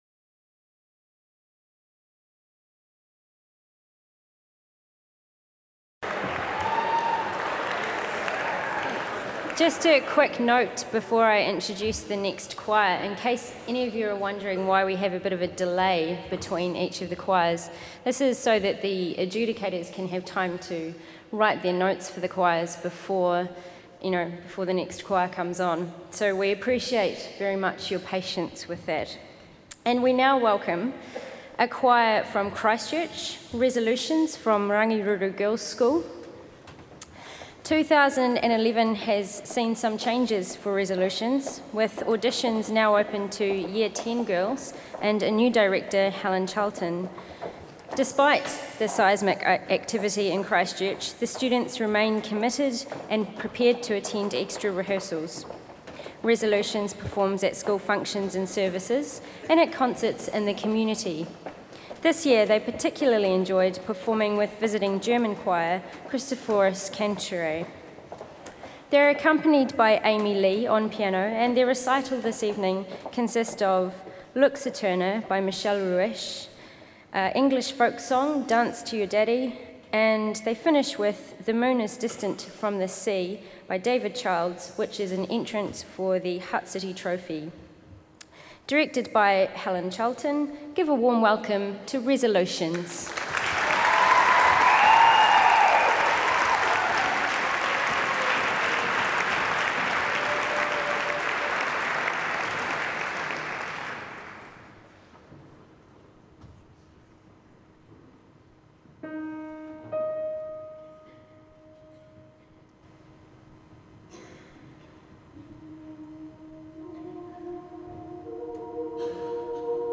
Recordings from The Big Sing National Final.